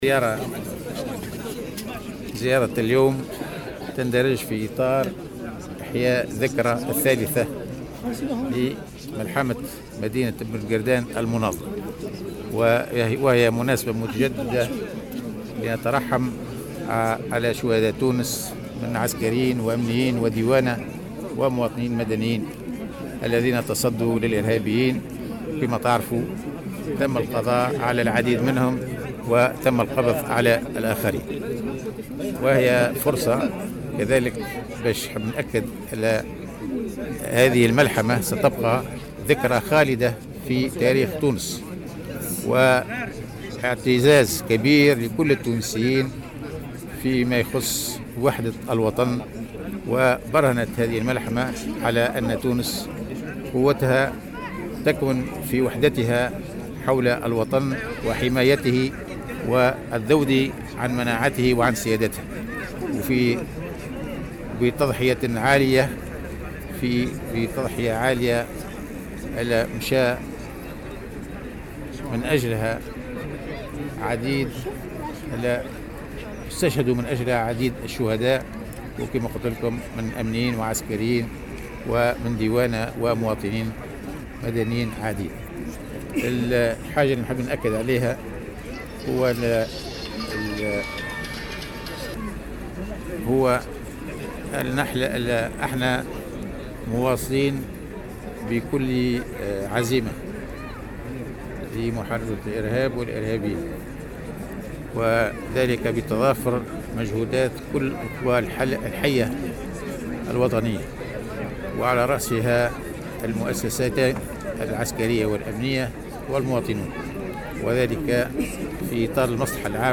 وقال وزير الدفاع عبد الكريم الزبيدي في تصريح لمراسل "الجوهرة اف أم" في استذكار للملحمة بنقردان ان هذه الملحمة اثبتت أن قوة تونس تكمن في وحدتها. كما اكد وزير الدفاع أن الحل الأمني والعسكري يبقى حلا منقوصا دون تركيز منظومة تنموية شاملة خاصة بالنسبة للمناطق الحدودية.